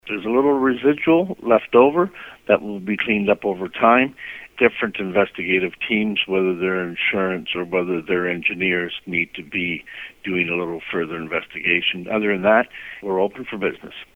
Mayor John Grace says OPP along with the Transportation Safety Board and Transport Canada are about to finish their investigation into the crash.